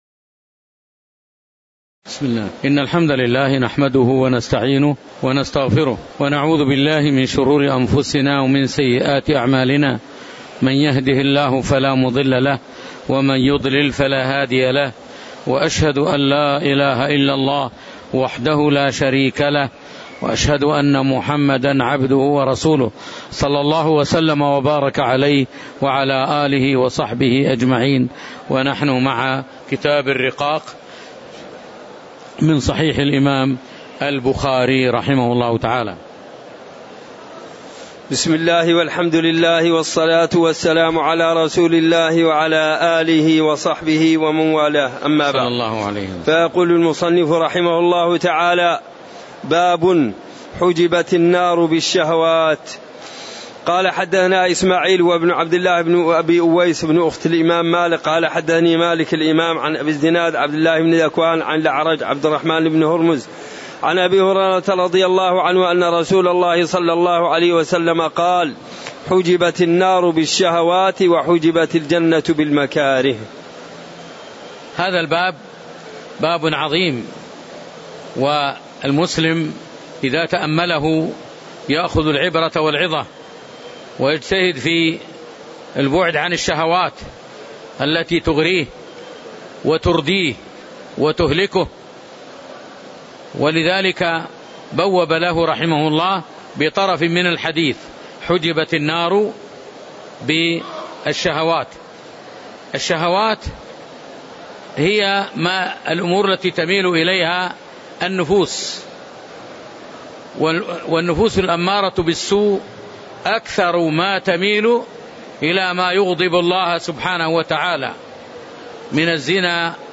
تاريخ النشر ١٣ رمضان ١٤٣٩ هـ المكان: المسجد النبوي الشيخ